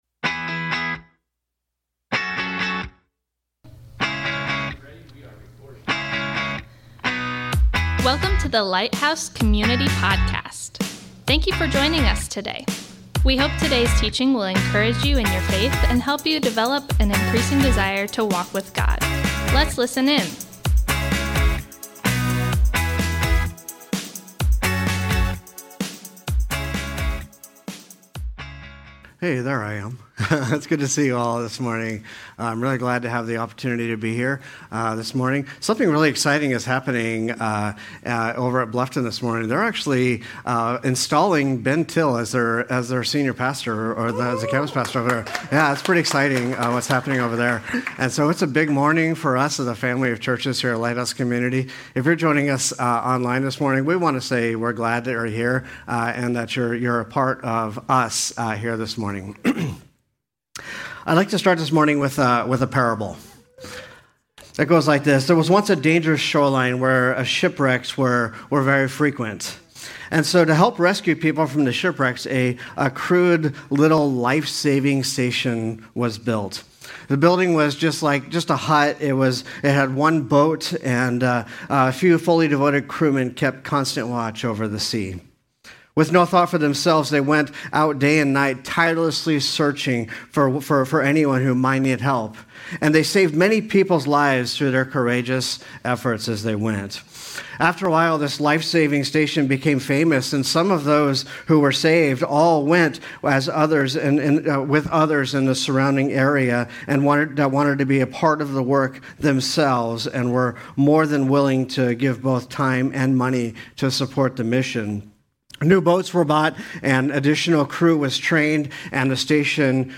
Thanks for joining us today as we worship together.